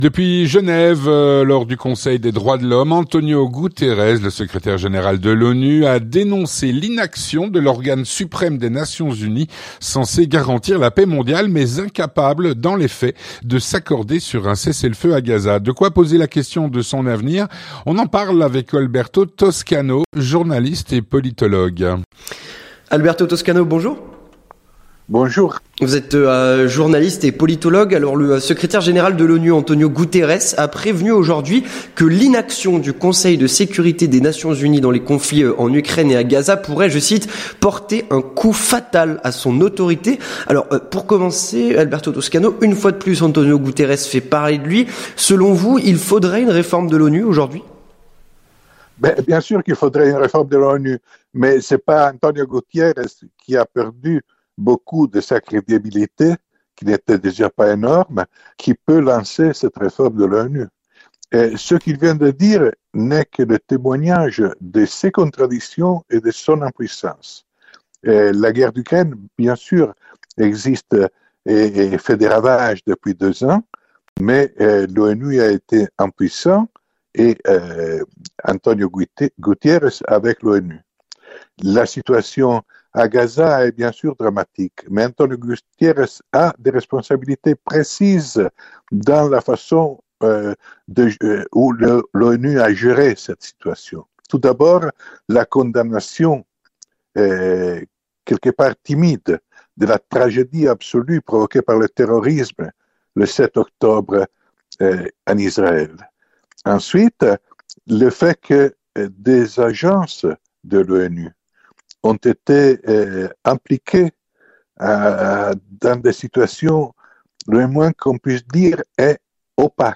journaliste et politologue.